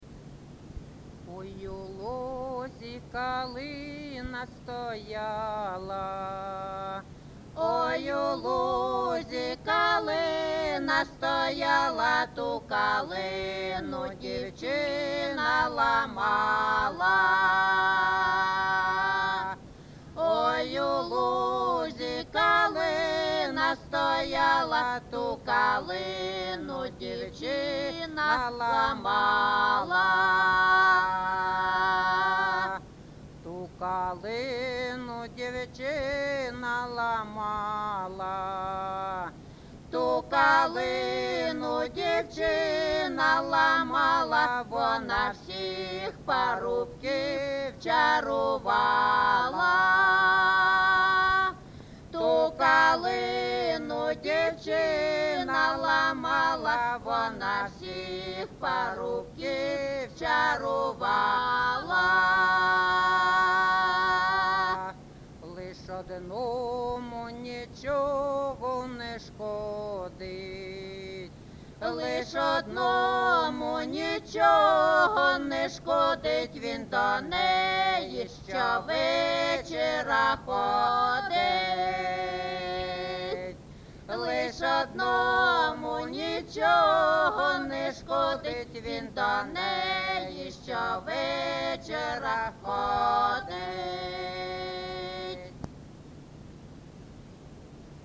ЖанрПісні з особистого та родинного життя
Місце записус-ще Калинівка, Бахмутський район, Донецька обл., Україна, Слобожанщина